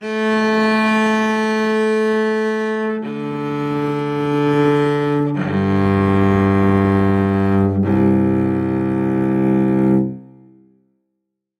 Звуки виолончели
Звук виолончели одна нота е